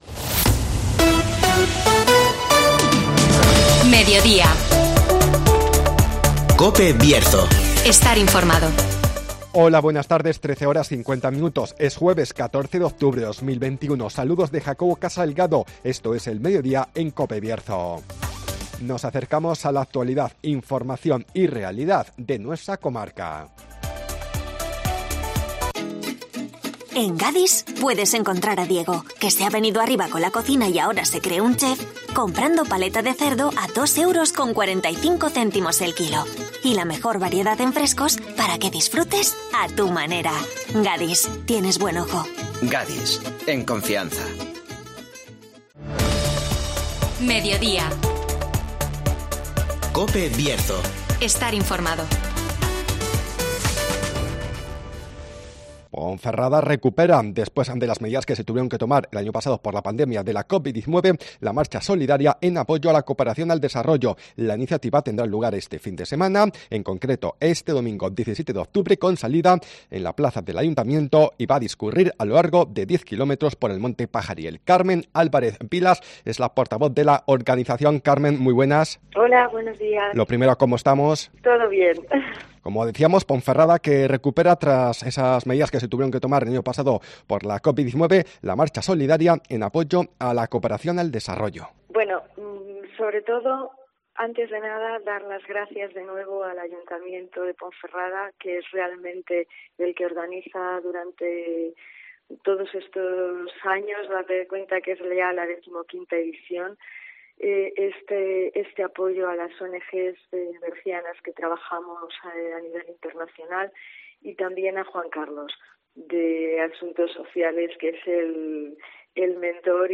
Ponferrada recupera este domingo la marcha solidaria en apoyo a la cooperación al desarrollo (Entrevista